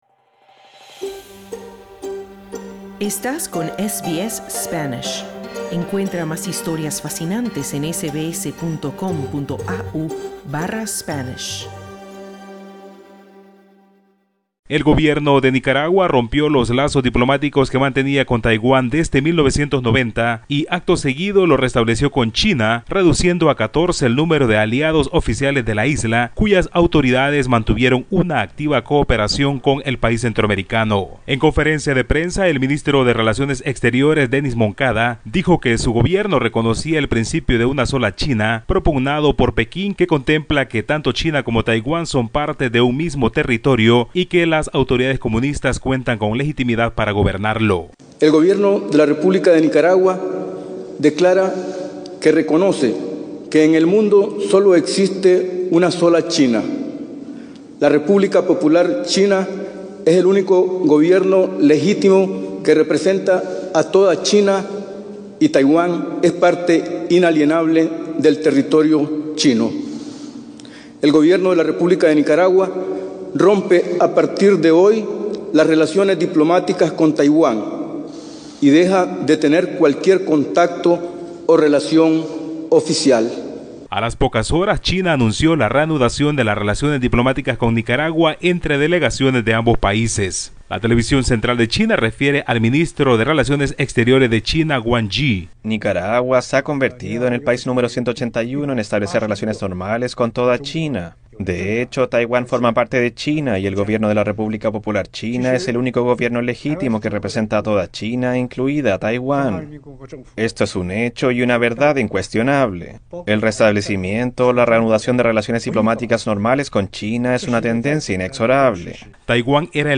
Escucha el informe del corresponsal de SBS Spanish en Latinoamérica